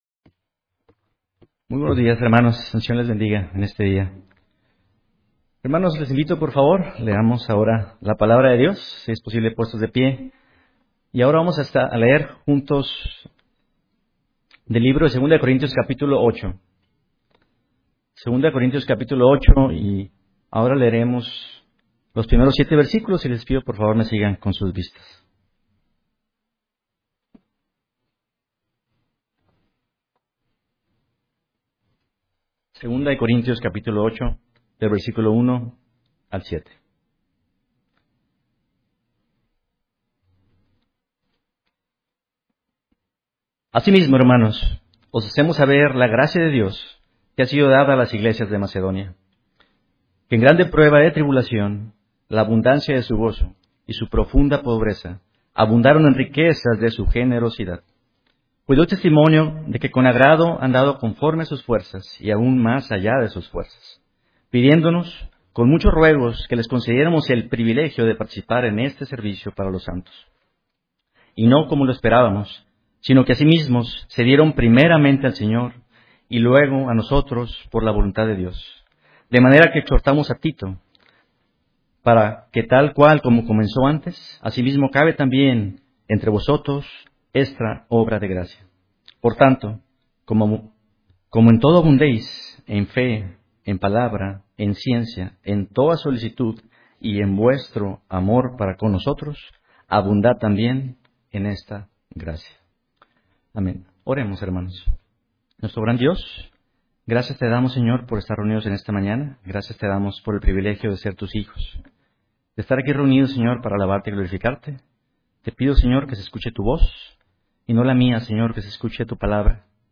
Sermón Generosidad en la vida cristiana, 2024-08-11